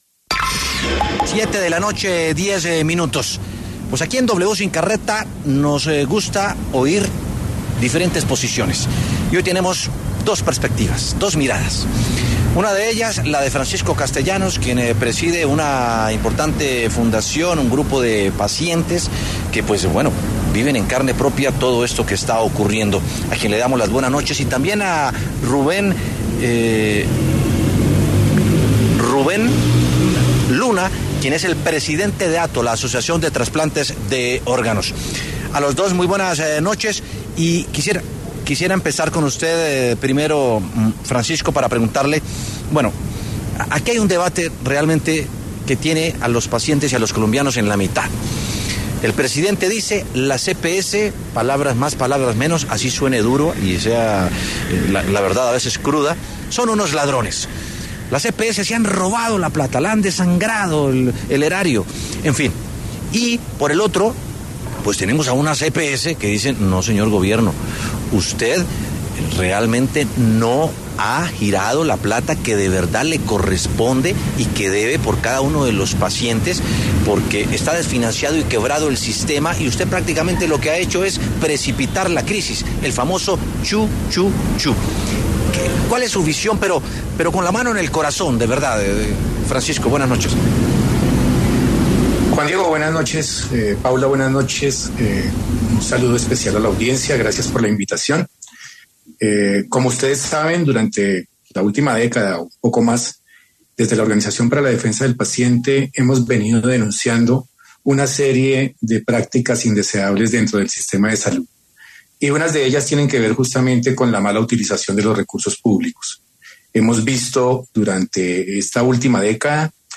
En W Sin Carreta estuvieron dos expertos que debatieron sobre el sistema de salud colombiano.